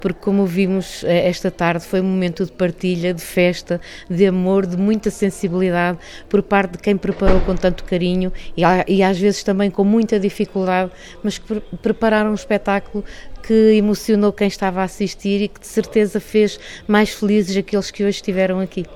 A vice-presidente da Câmara Municipal de Macedo de Cavaleiros, Clementina Gemelgo, enalteceu igualmente a Festa de Natal Sénior como um dos momentos marcantes desta quadra natalícia: